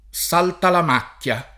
[ S altalam # kk L a ]